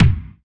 • Bass Drum F Key 54.wav
Royality free kick sound tuned to the F note. Loudest frequency: 479Hz
bass-drum-f-key-54-Gcd.wav